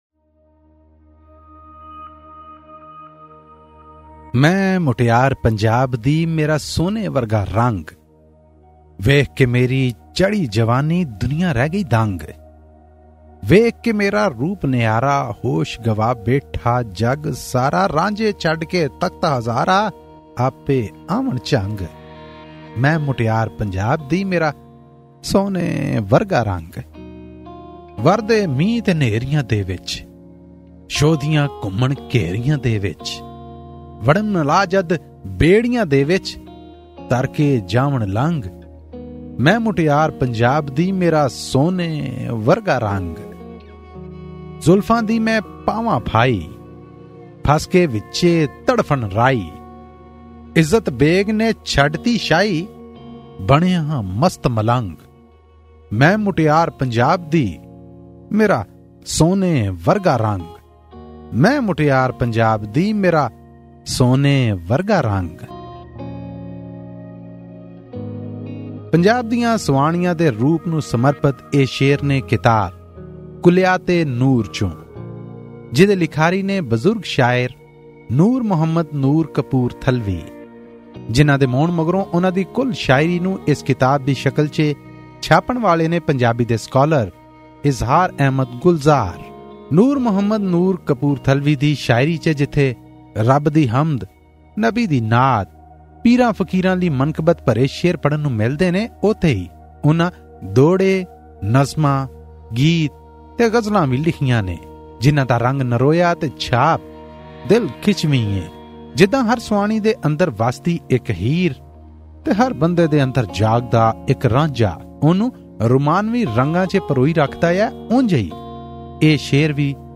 Pakistani Punjabi poetry book review: 'Kuliyat-E-Noor' by Noor Muhammad Noor Kapoor Thalvi